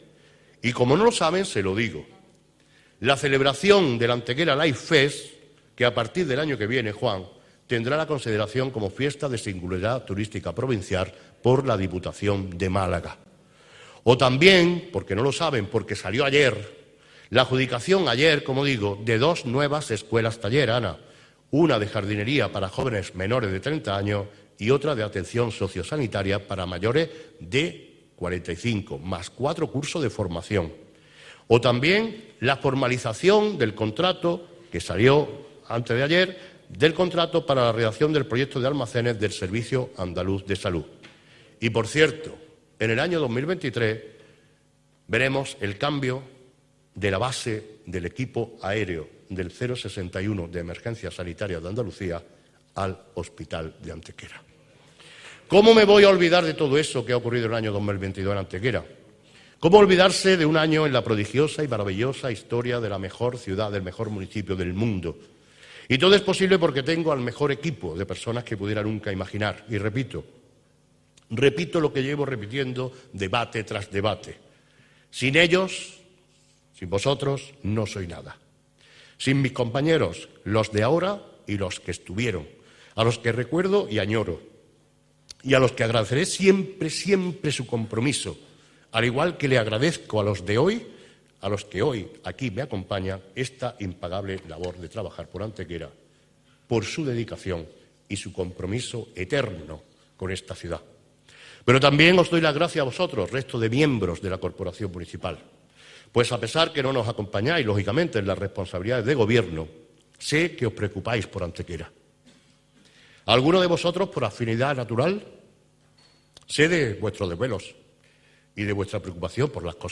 Intervención del Alcalde de Antequera en el Debate del Estado de la Ciudad (Pleno del viernes 23 de diciembre de 2022)
Cortes de voz